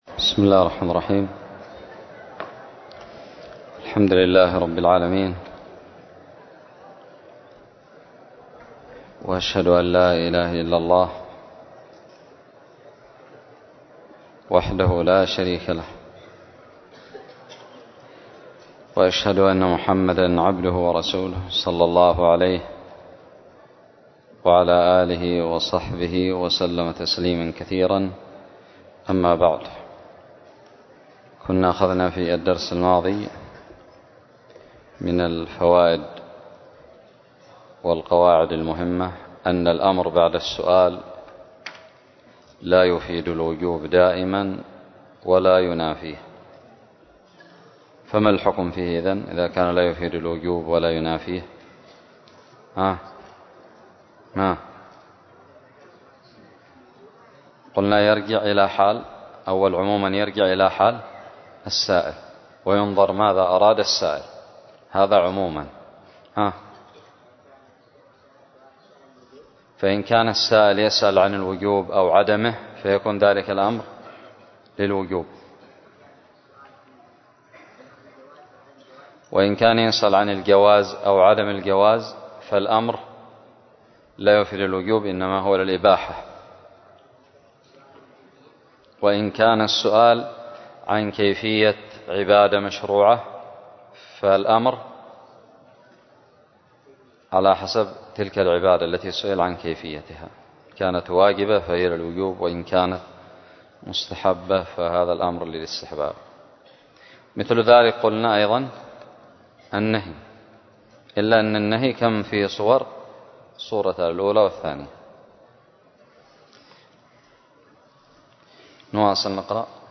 الدرس الثاني عشر من شرح كتاب المنتقى للمجد ابن تيمية
ألقيت بدار الحديث السلفية للعلوم الشرعية بالضالع